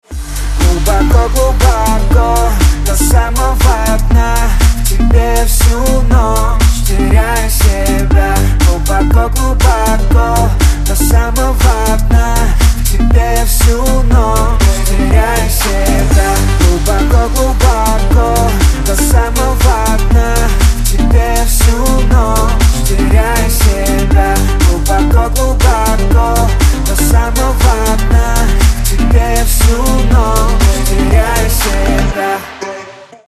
Танцевальные